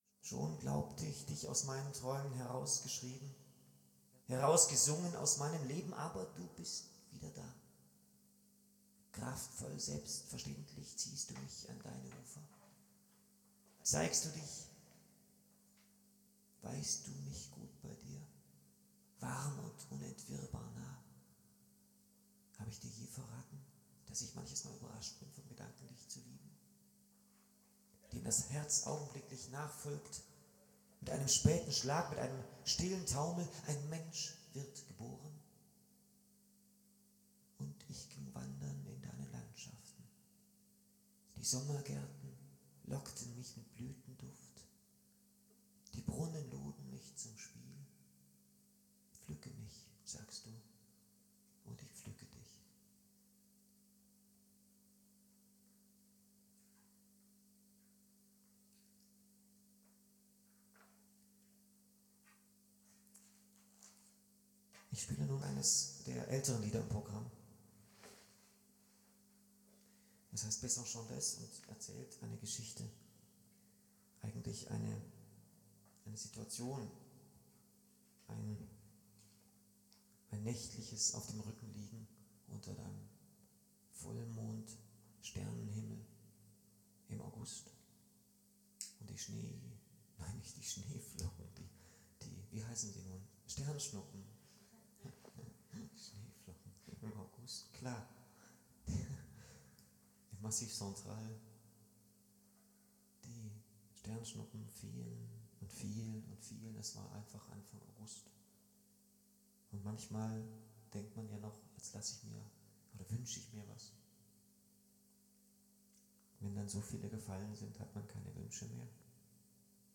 (tipp: die ogg-datei klingt wesentlich besser)